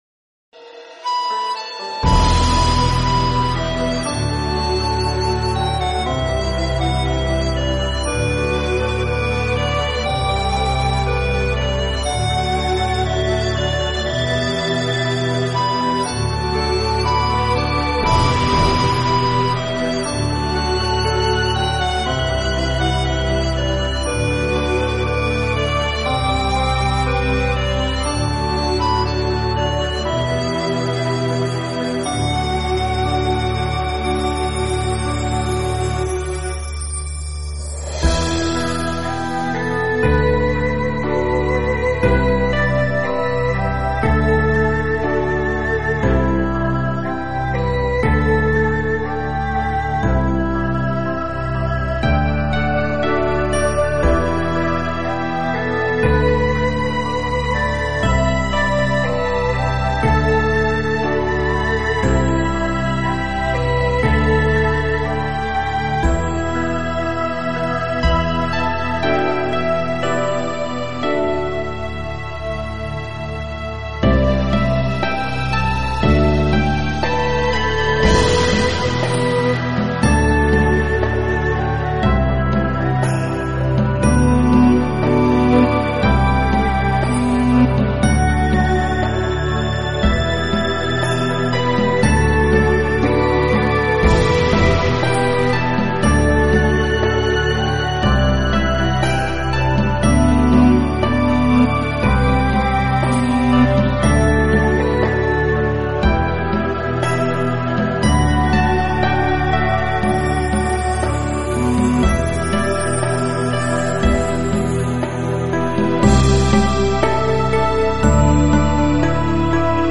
佛教音乐